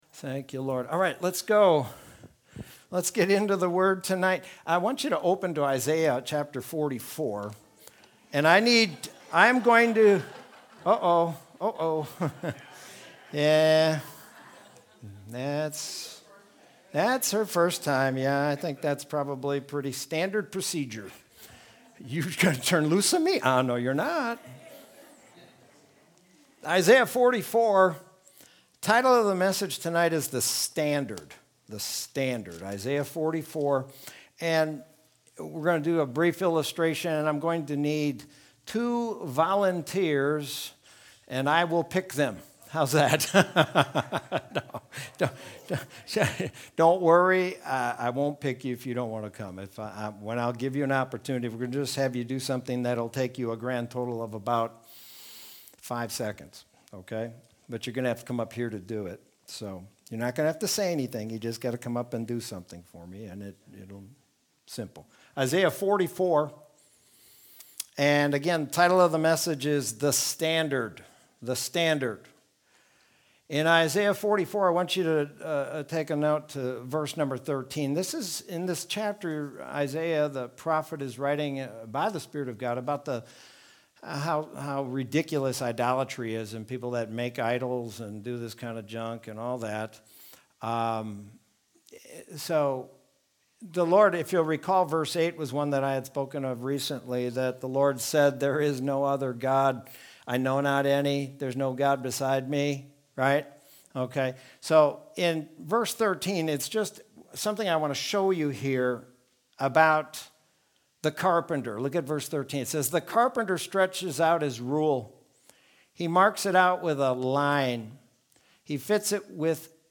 Sermon from Wednesday, April 28th, 2021.